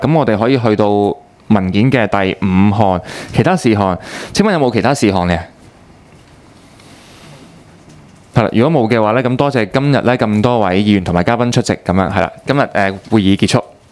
中西區區議會 - 委員會會議的錄音記錄
會議的錄音記錄